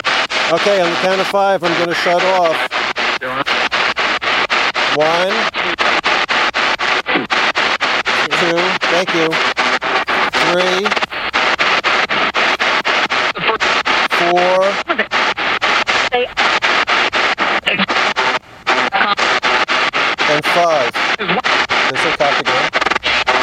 Rose Hill 5 GB #13  -   I am doing my count down to end the session.   After I say one,  we all hear the box reply "Two"!  Then after I say three,  it almost sounds like a spirit says four!